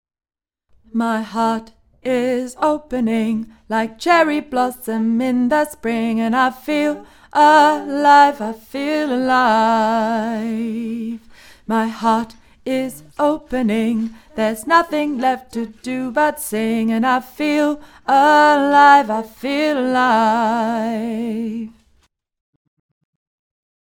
Cherry+Blossom+-+Tenor+mp3.mp3